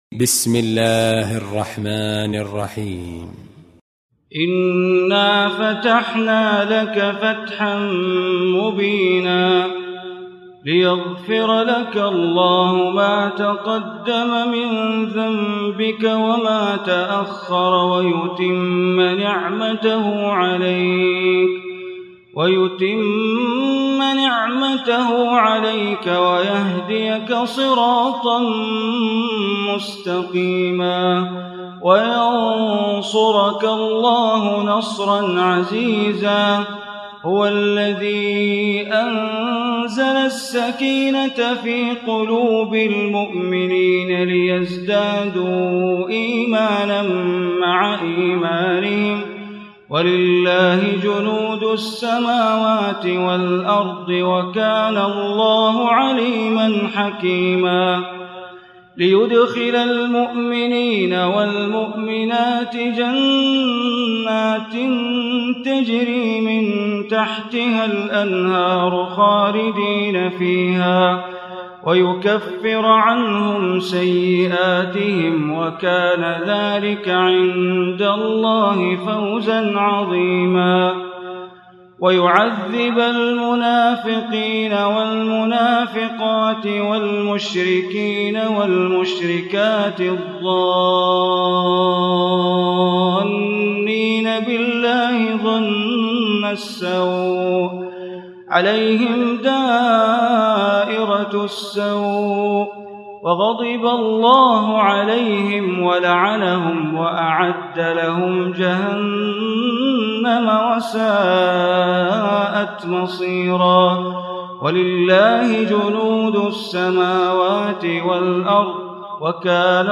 Surah Al-Fath Recitation by Sheikh Bandar Baleela
Surah Al Fath, listen online mp3 tilawat / recitation in Arabic, recited by Imam e Kaaba Sheikh Bandar Baleela.